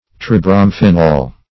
Search Result for " tribromphenol" : The Collaborative International Dictionary of English v.0.48: Tribromophenol \Tri*bro`mo*phe"nol\, Tribromphenol \Tri`brom*phe"nol\, n. (Chem.) A colorless crystalline substance ( C6H3OBr3 ) prepared by the reaction of carbolic acid with bromine.